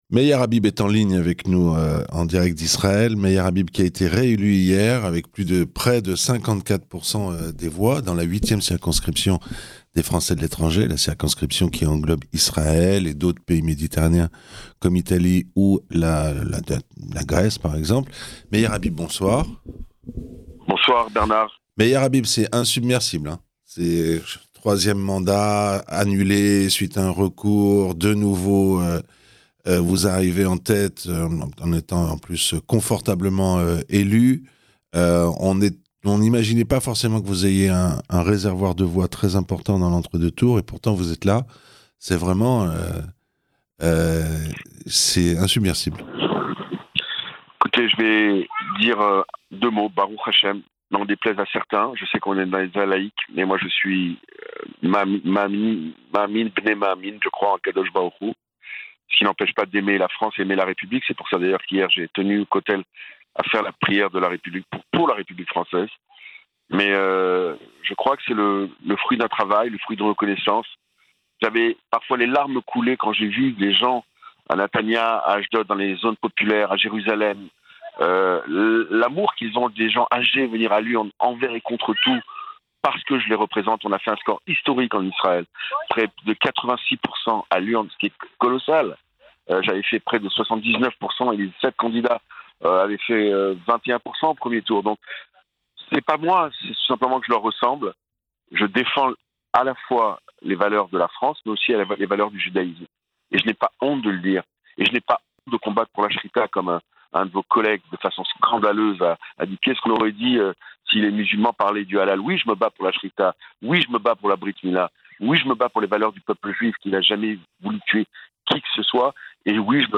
Apparenté LR Meyer Habib a récolté 53,99 % des voix, contre 46,01 % pour la macroniste Deborah Abisror de Lieme, Interview